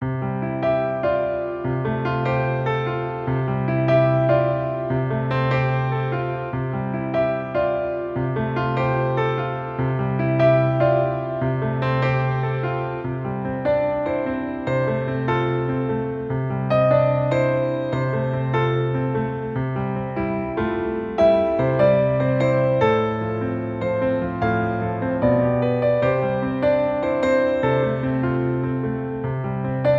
• Key: C Major
• Instruments: Piano solo
• Genre: Pop